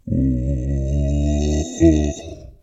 zombie-3.ogg